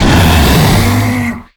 attack_hit_4.ogg